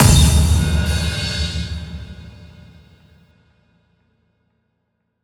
Index of /musicradar/cinematic-drama-samples/Impacts
Impact 04.wav